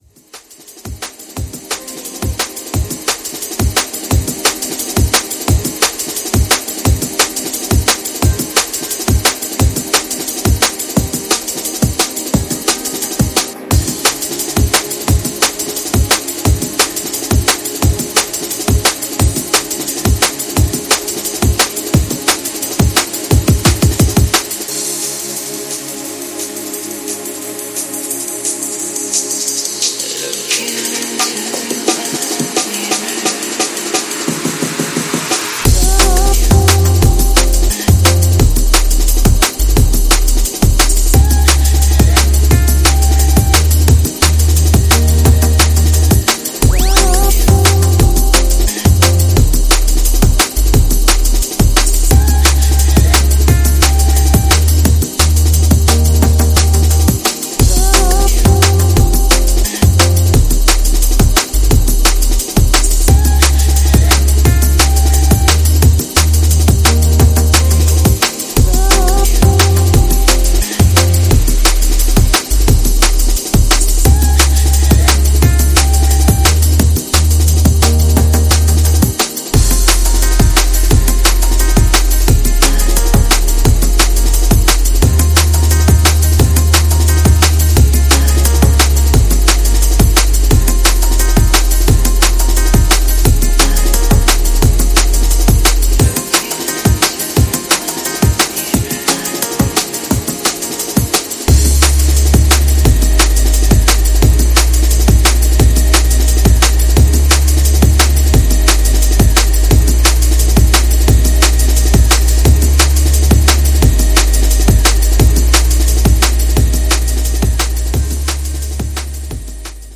Styl: Drum'n'bass Vyd�no